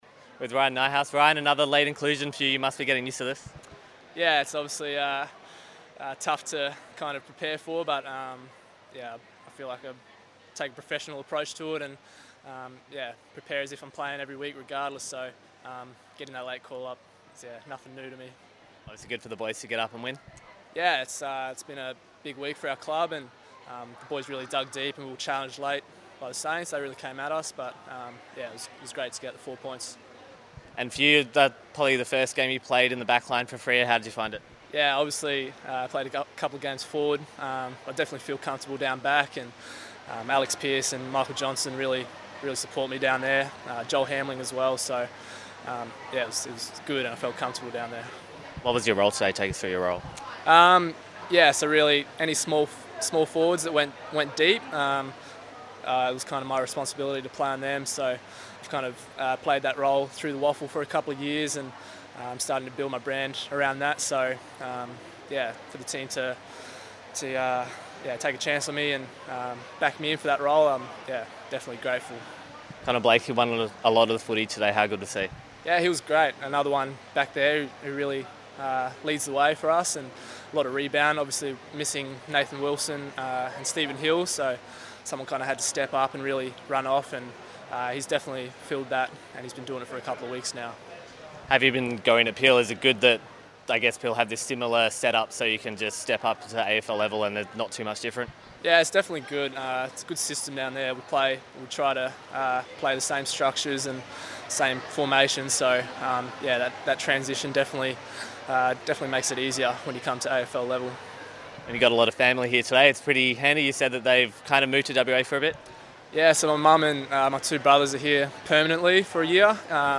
post-match interview - Round 8 v St Kilda